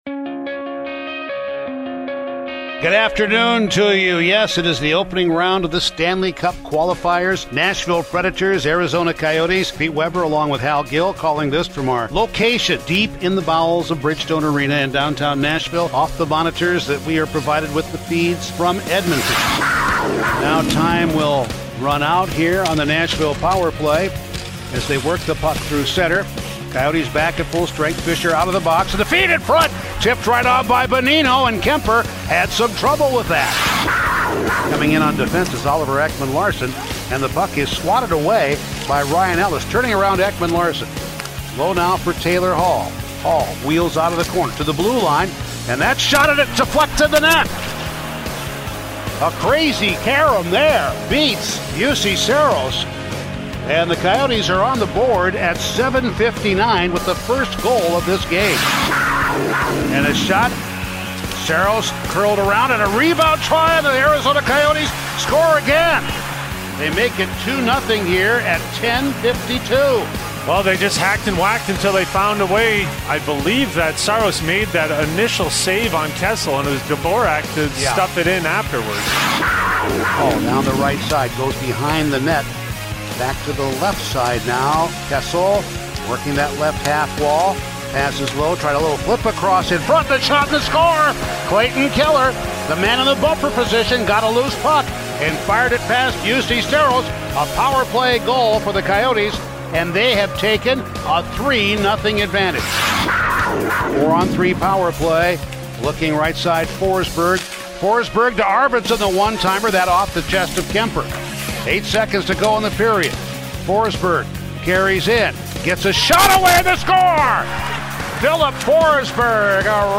Full radio highlights from the Preds 4-3 loss to the Coyotes in Game 1 of the Western Conference Qualifying Round on August 2, 2020